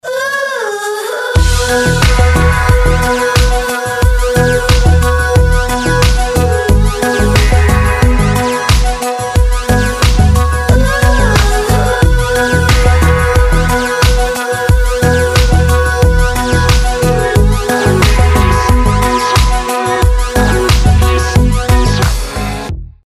поп
dance
Electropop
vocal